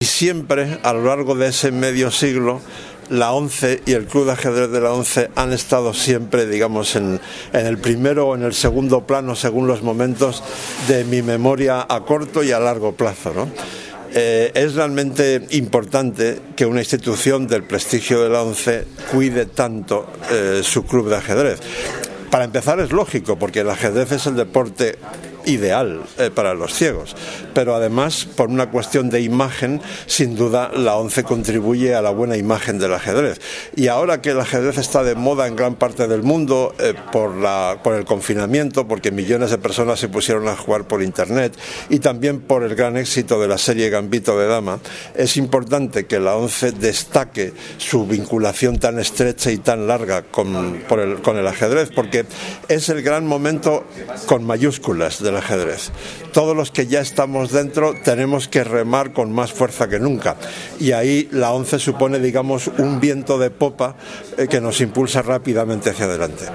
El periodista español especializado en ajedrez Leontxo García, vinculado a El País desde 1985, ofreció, el pasado 22 de septiembre, una charla-conferencia en el salón de actos de la Delegación Territorial de la ONCE en Madrid bajo el título “El ajedrez ayuda a pensar”